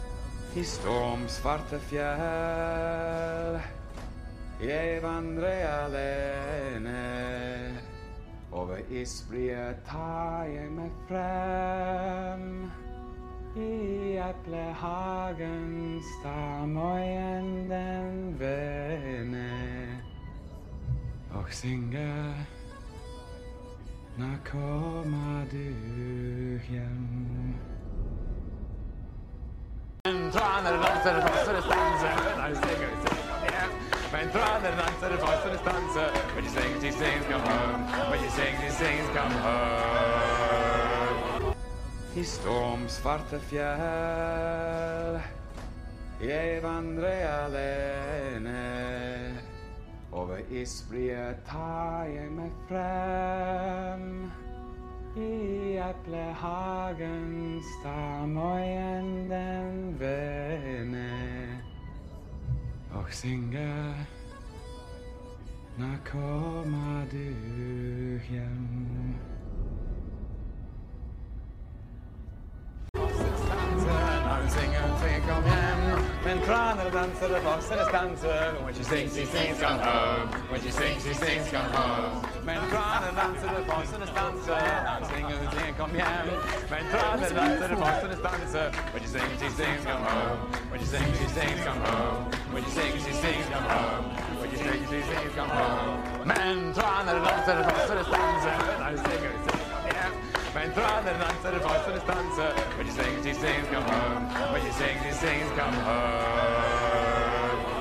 Trinklied